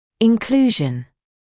[ɪn’kluːʒn][ин’клуːжн]включение